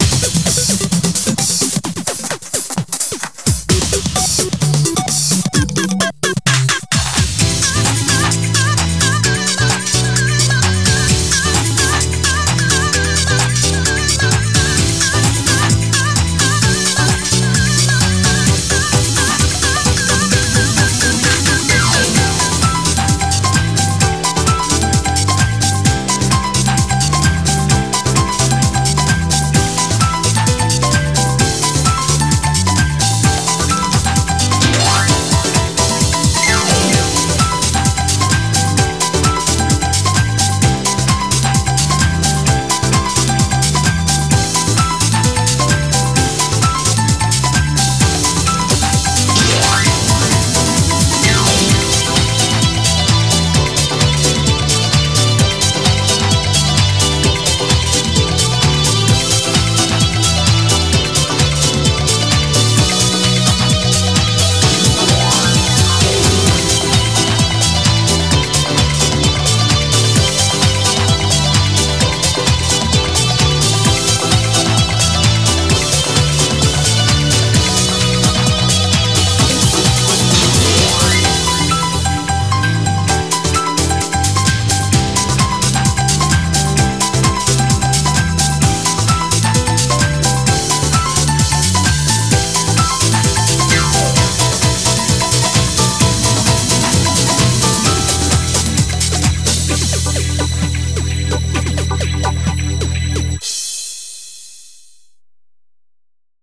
BPM130
Better quality audio.